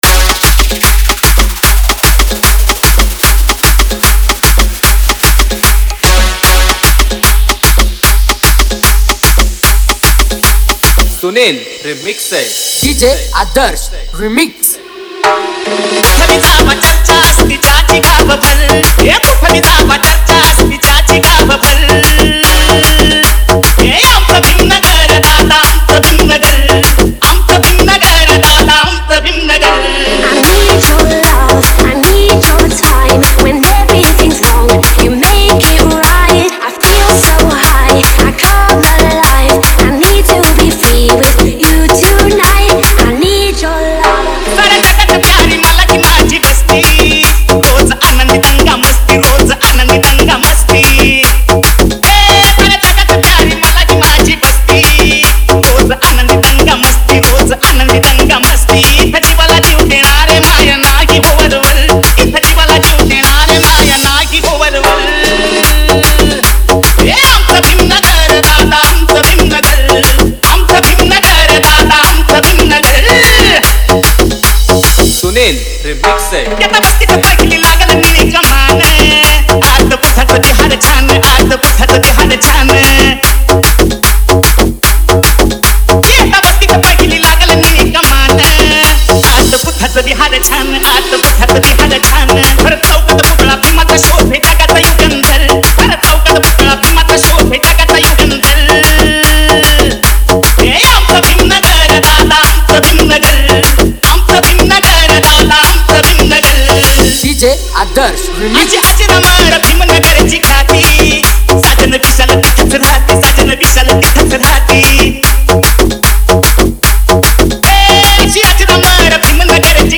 #1 New Marathi Dj Song Album Latest Remix Releases